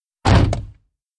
BaBoOn-R44-Door_close.wav